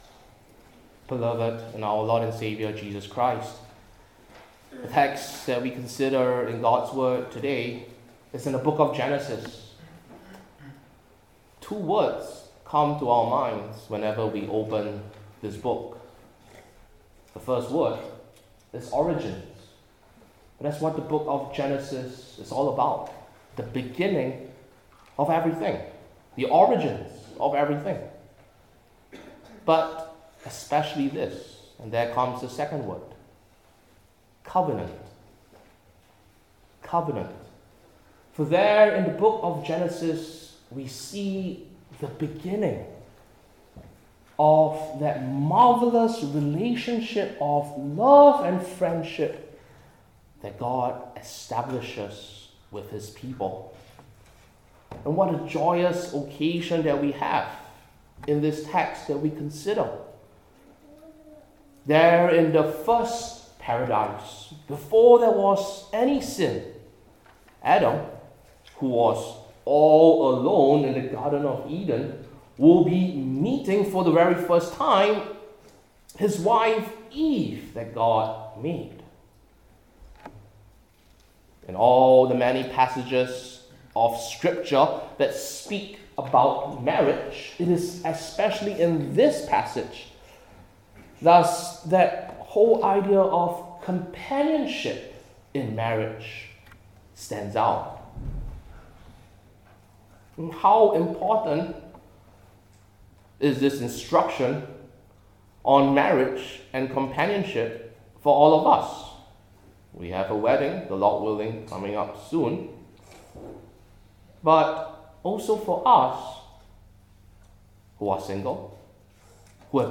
Old Testament Individual Sermons I. What?